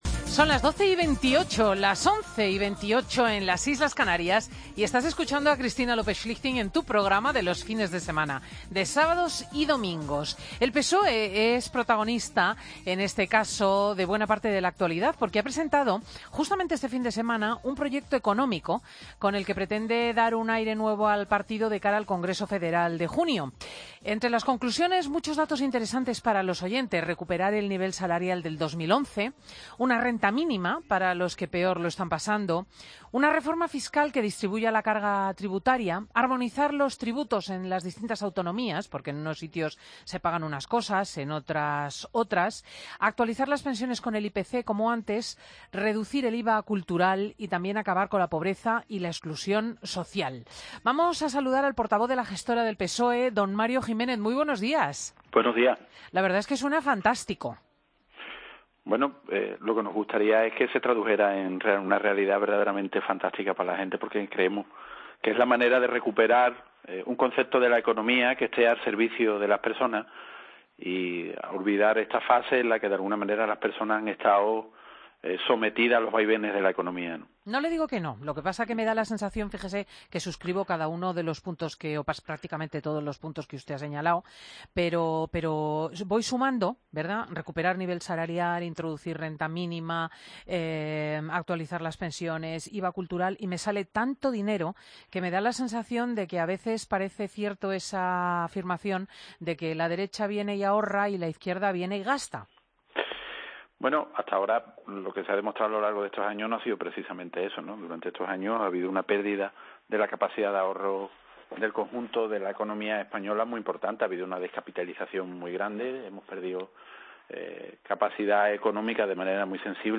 AUDIO: Escucha la entrevista a Mario Jiménez, portavoz de la Comisión Gestora del PSOE, en 'Mediodía COPE'